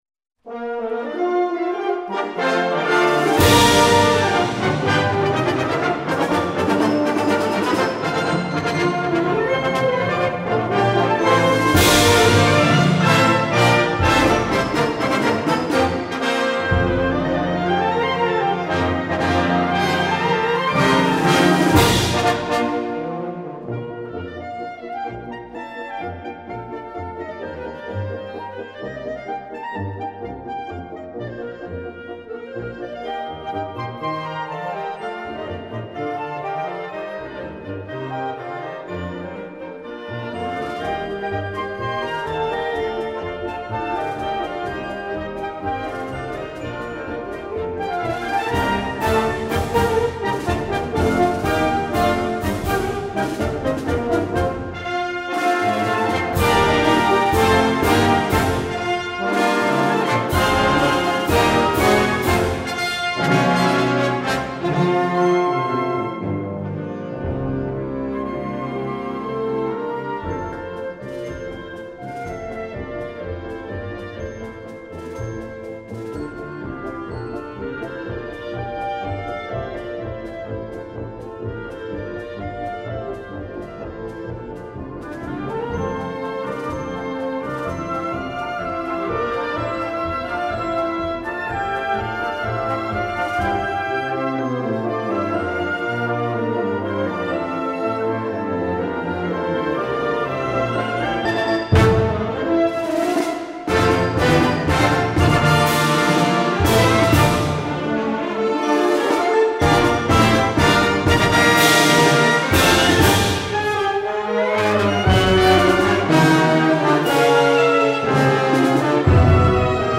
Gattung: Ouvertüre für Blasorchester
Besetzung: Blasorchester